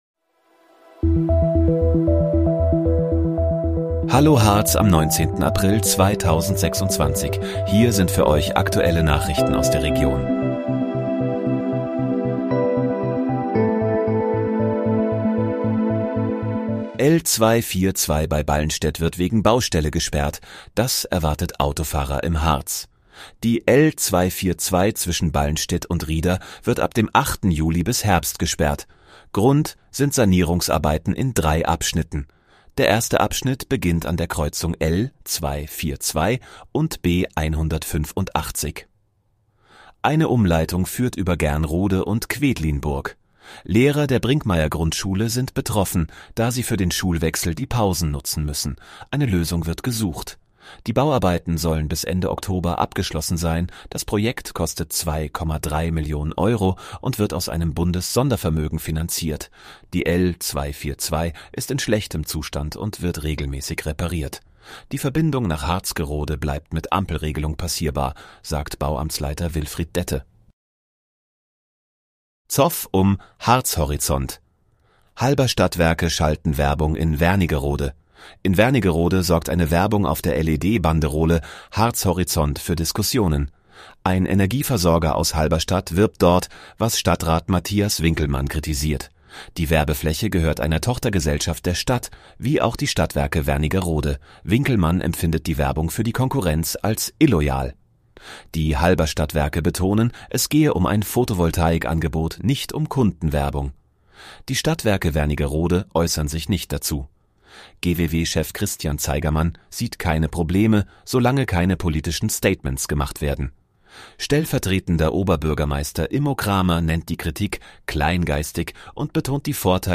Hallo, Harz: Aktuelle Nachrichten vom 19.04.2026, erstellt mit KI-Unterstützung